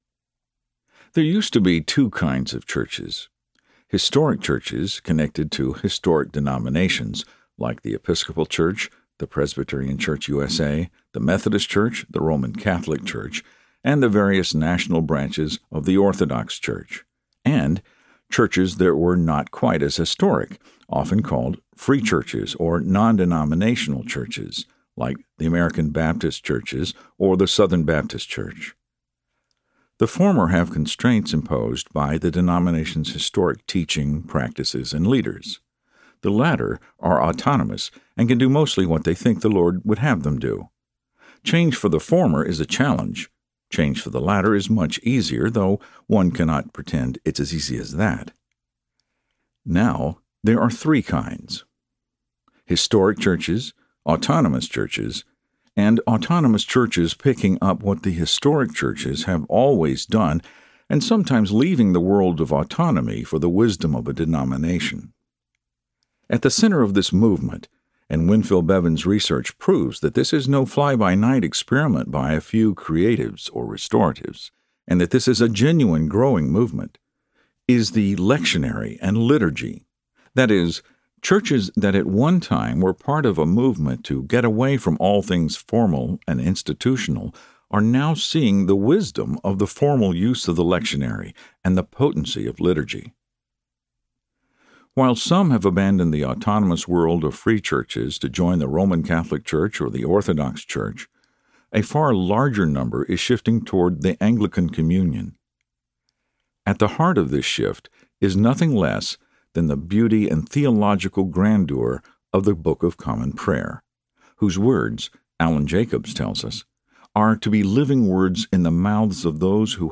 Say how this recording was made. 6.2 hrs. – Unabridged